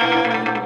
12 Harsh Realm Guitar Roll.wav